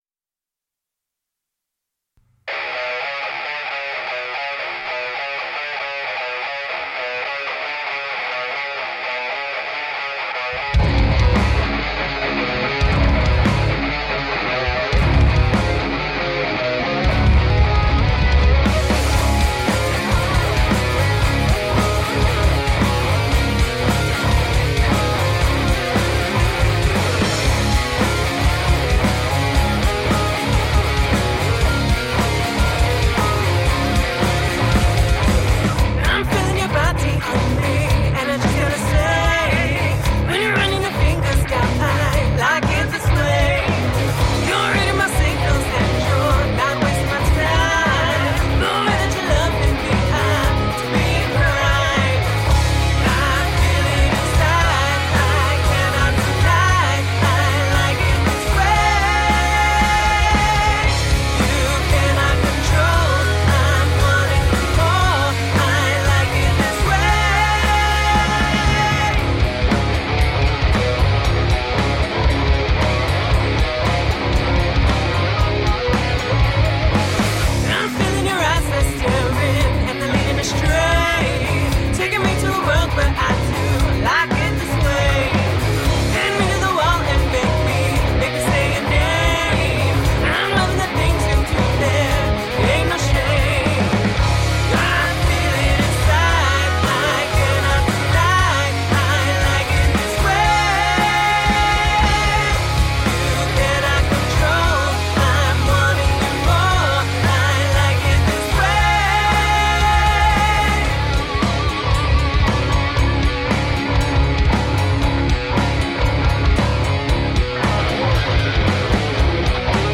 heavy, melodic